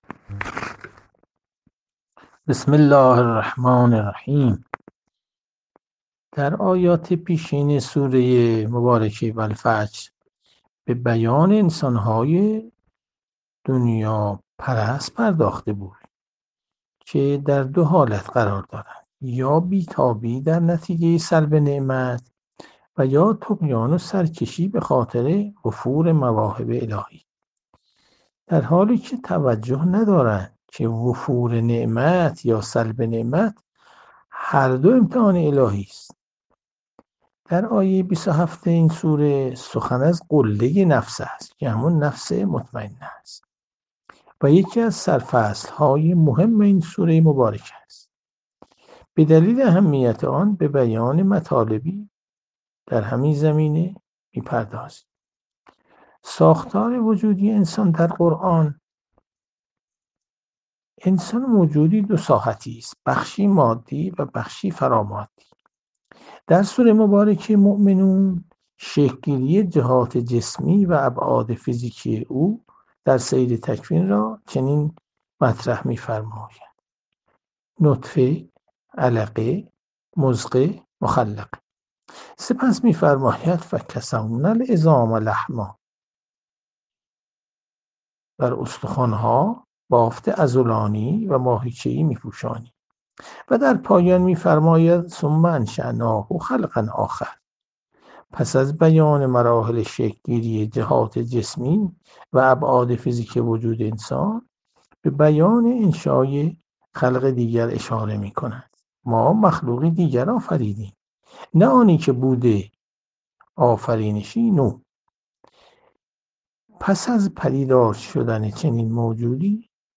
تفسیر قرآن
جلسات مجازی